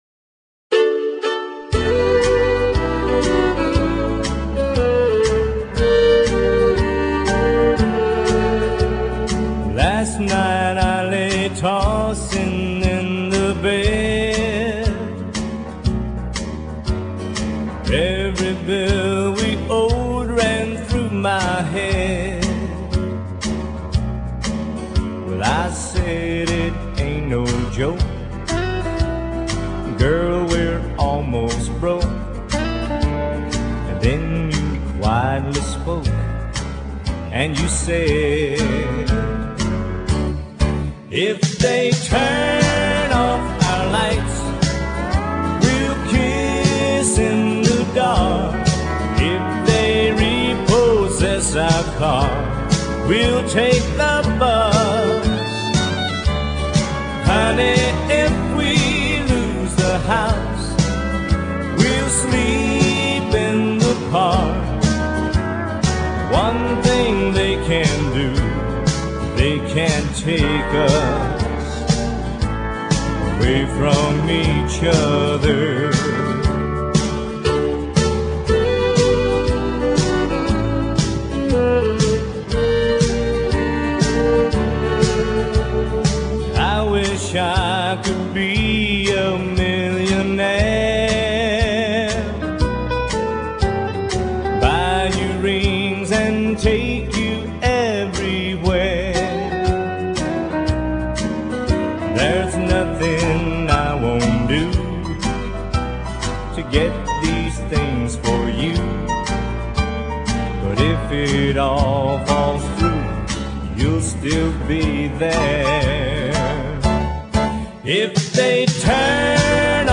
乡村音乐不像纯古典音乐離自己很遥远；也不像摇滚、重金属音乐那样嘈杂。